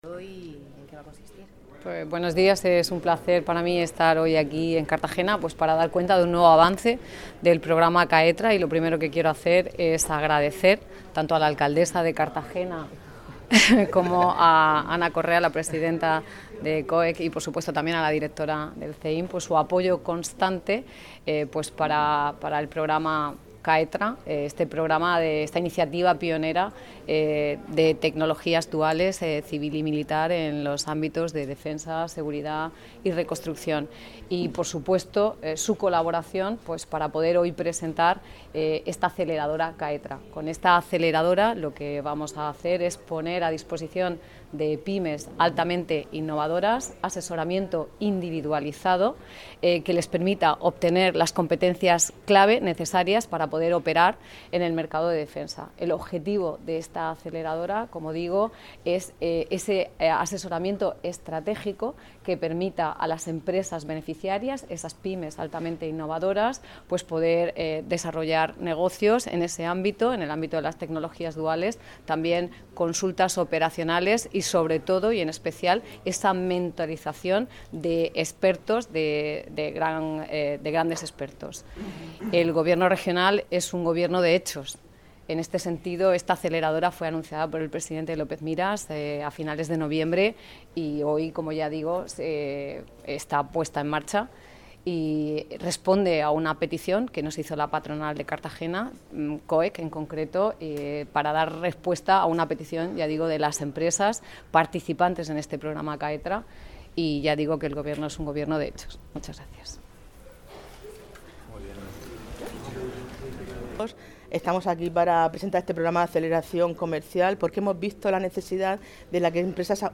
Audio: Declaraciones de Noelia Arroyo en el CEEIC (MP3 - 3,19 MB)